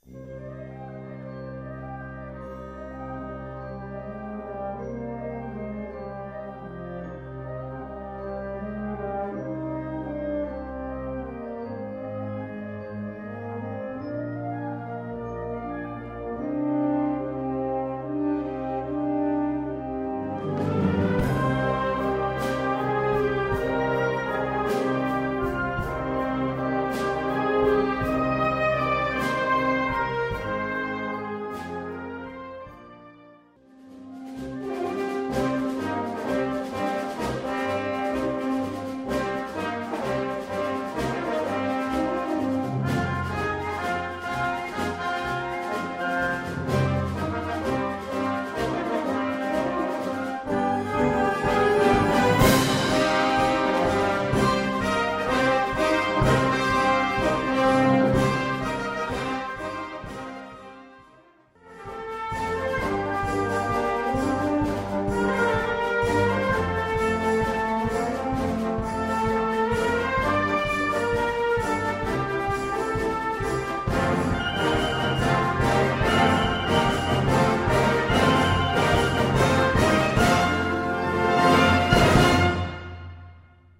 Category Concert/wind/brass band
Subcategory Rhapsody
Instrumentation Ha (concert/wind band)